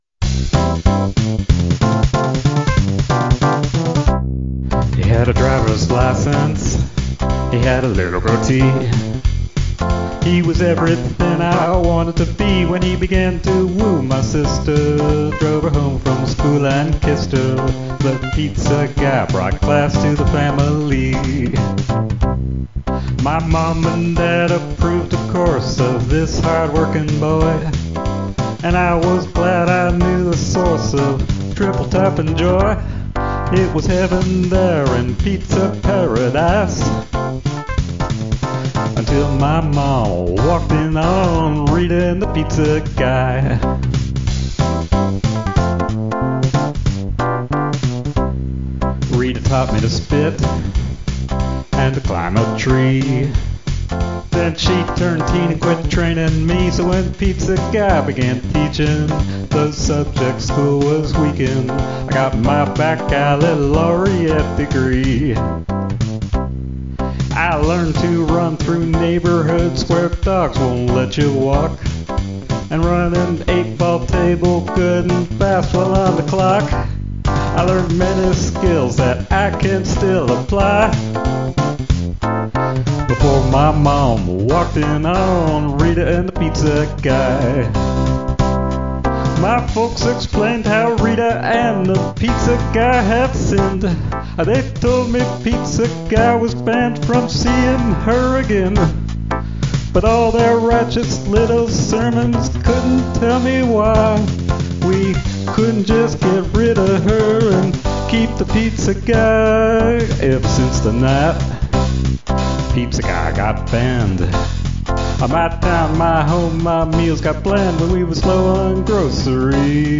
uptempo country, male or female voice